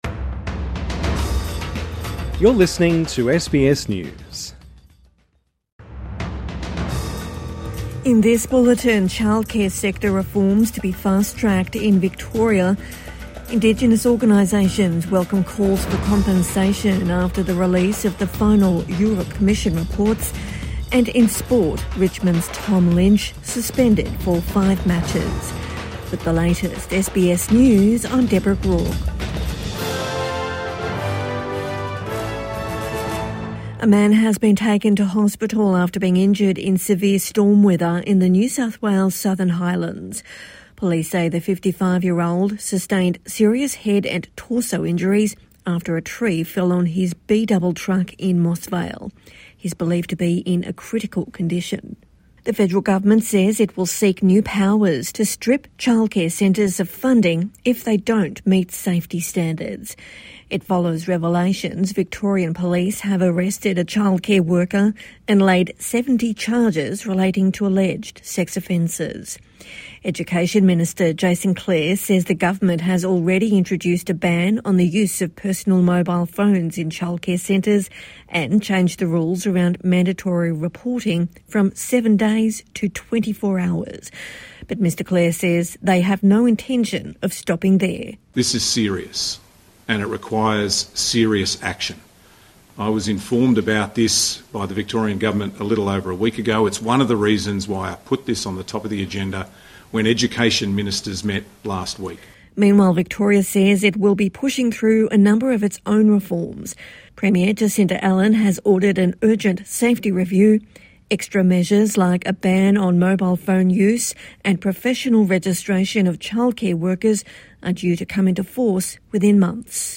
Victoria to fast-track childcare safety reforms | Evening News Bulletin 2 July 2025